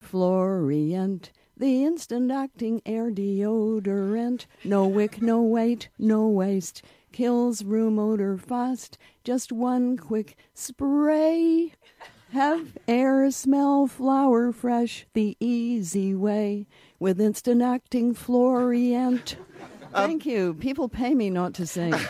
What better way to leave you in the morning than with a jingle sung by writer Margaret Atwood?